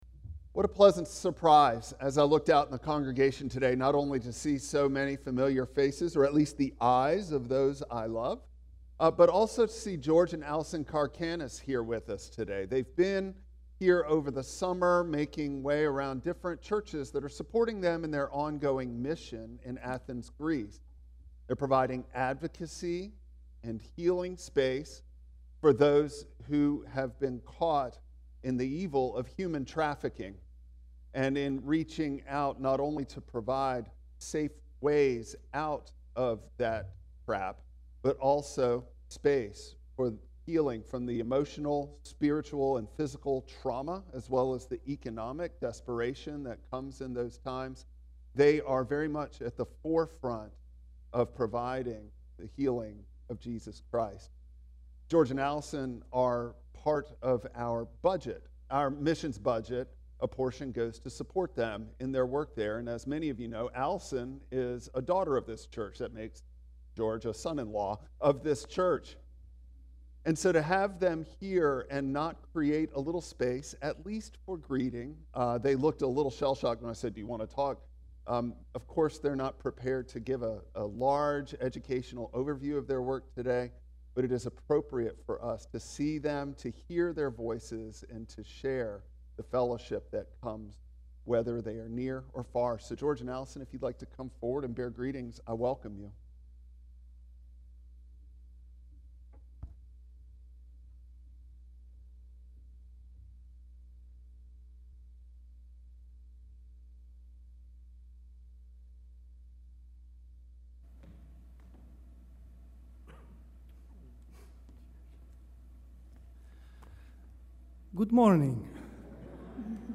Romans 12:1-21 Service Type: Traditional Service Bible Text